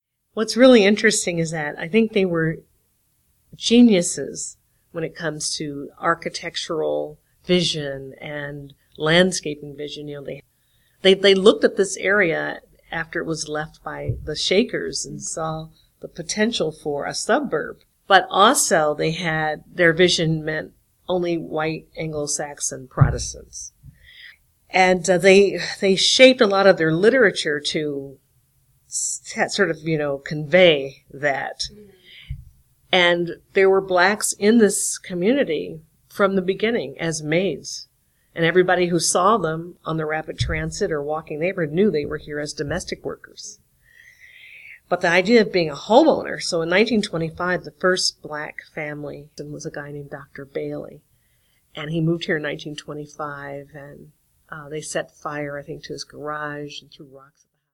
Cleveland Regional Oral History Collection